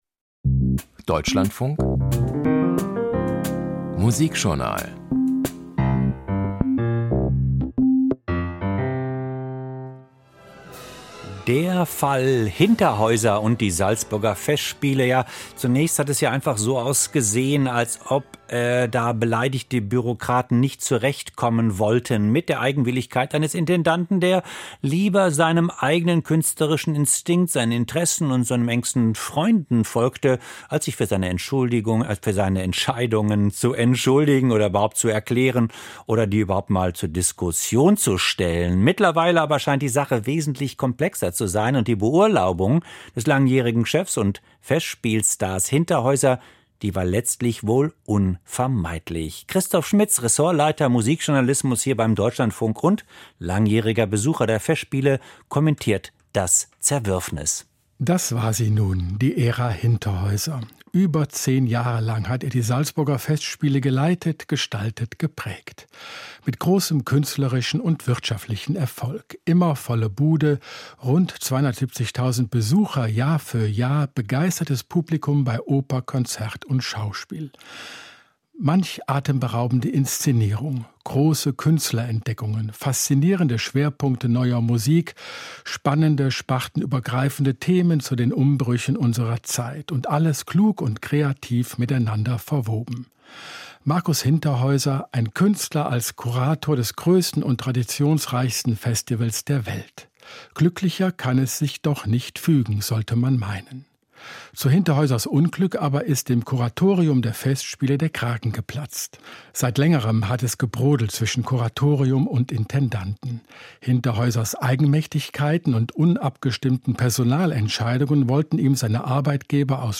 Kommentar: Salzburger Festspiel-Streit - Intendant Hinterhäuser muss gehen